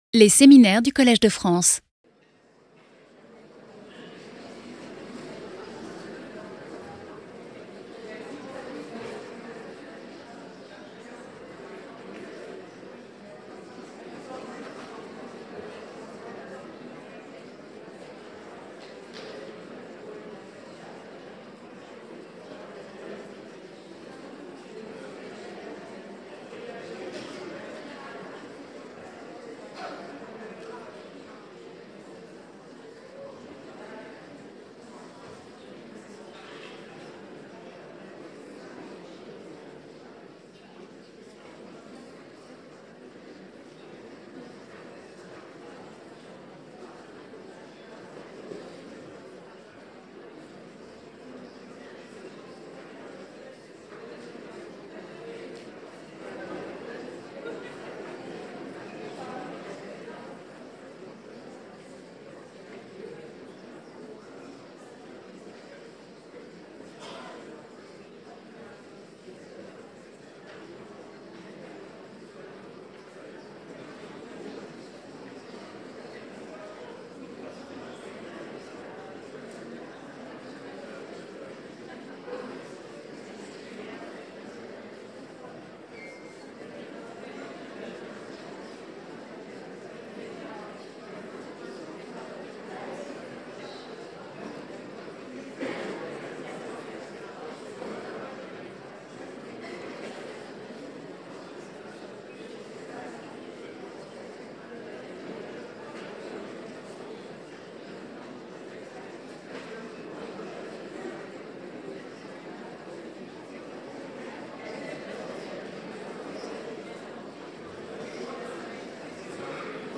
Seminar